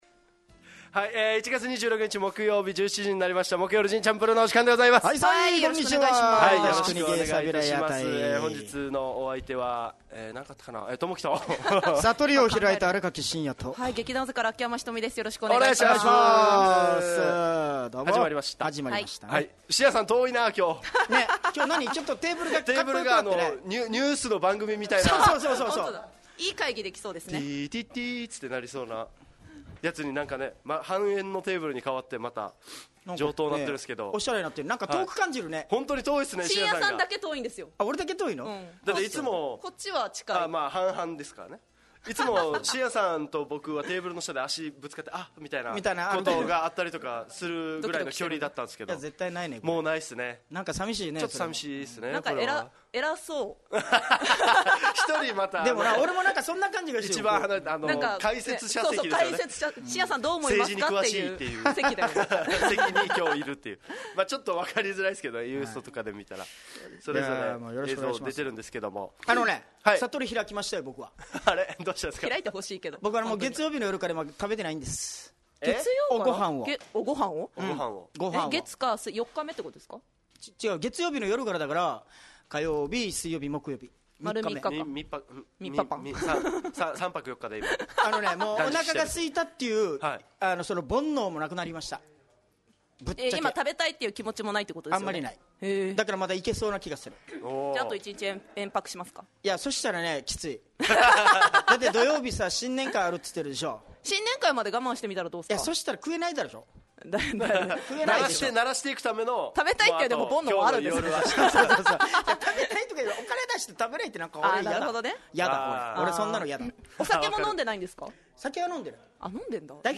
fm那覇がお届けする沖縄のお笑い集団・オリジンメンバー出演のバラエティ